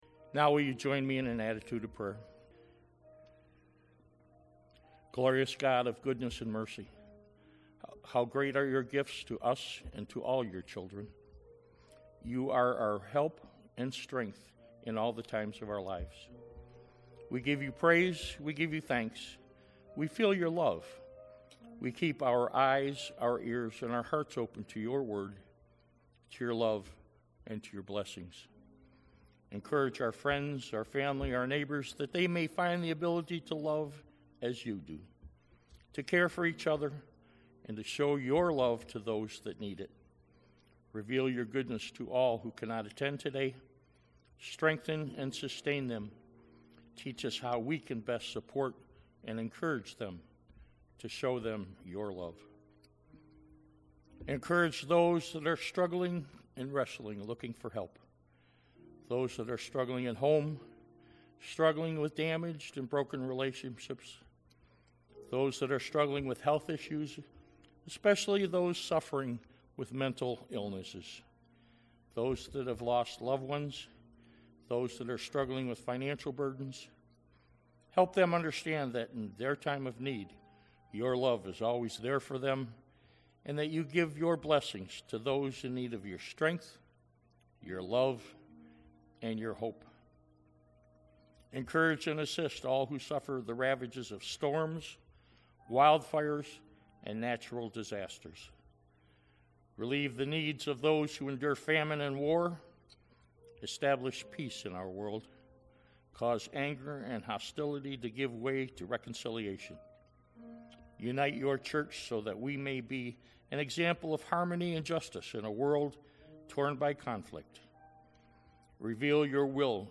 Audio Sermons